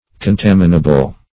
Search Result for " contaminable" : The Collaborative International Dictionary of English v.0.48: Contaminable \Con*tam"i*na*ble\ (k[o^]n*t[a^]m"[i^]*n[.a]*b'l), a. Capable of being contaminated.